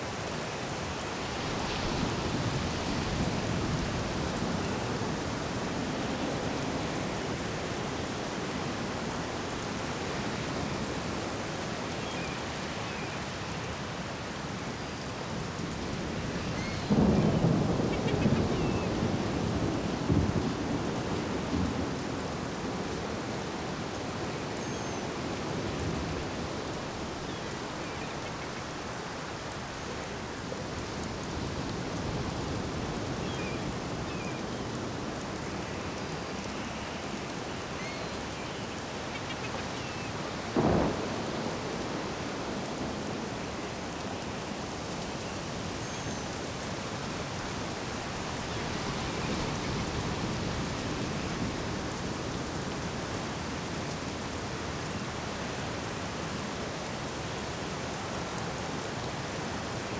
Beach Storm.ogg